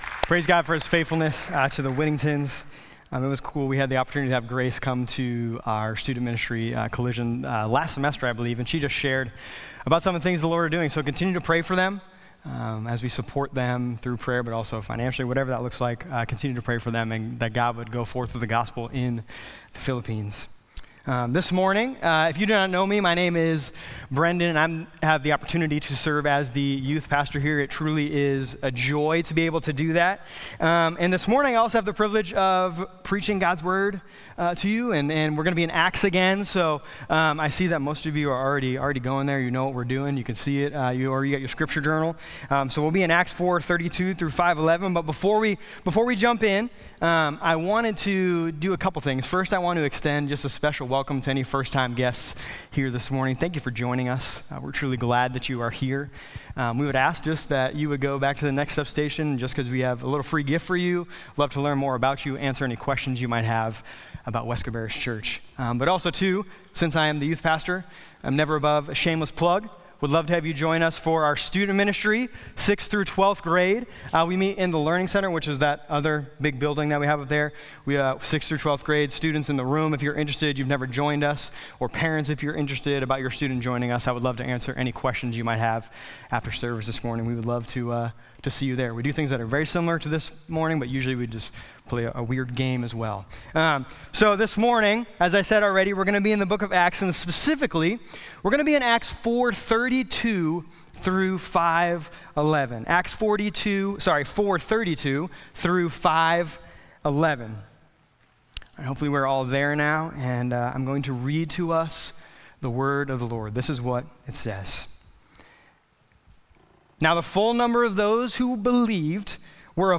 sermon-2-22-26.mp3